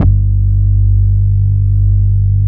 43 MOOG BASS.wav